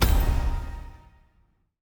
Special Click 04.wav